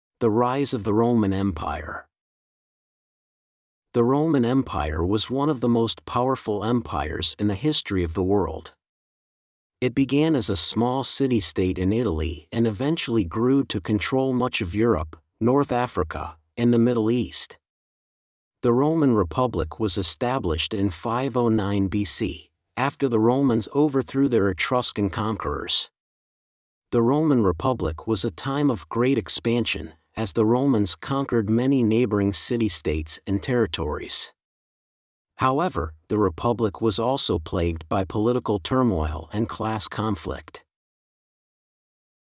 Two MP3s and a transcript: A narration about the event and a factual Q&A segment.